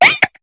Cri de Psystigri dans Pokémon HOME.